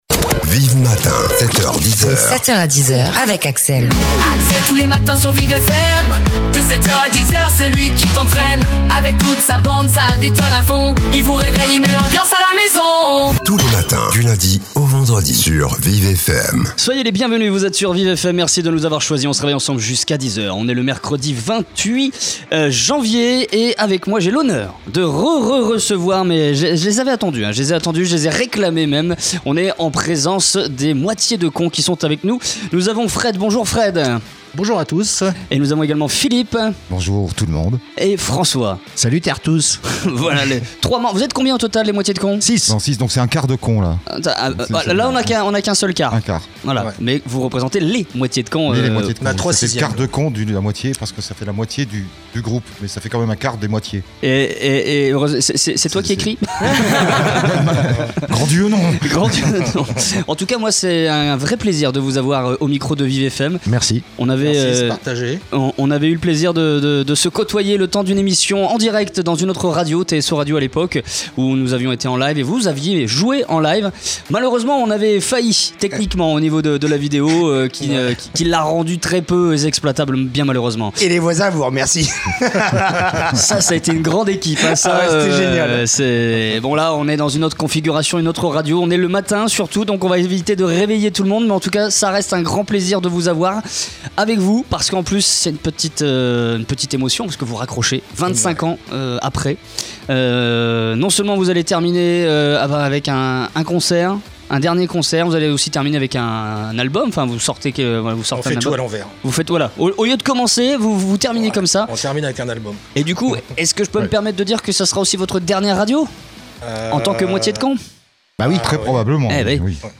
28-01 ITW DES MOITIES DE CON - VIVFM